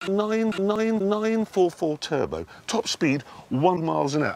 ytp-hammonds-soviet-ambulance.mp3